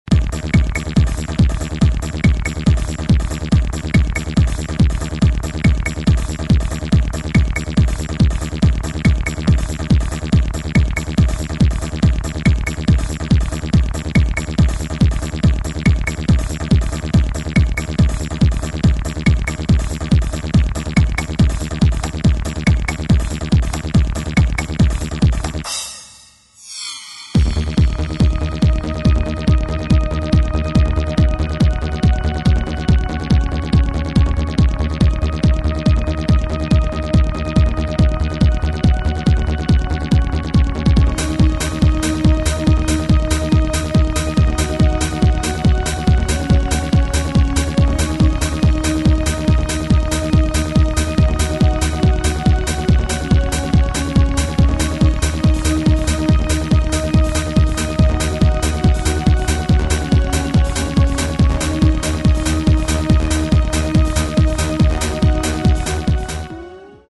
Género: Electronic
Estilo: Hard TranceTrance